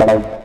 tekTTE63007acid-A.wav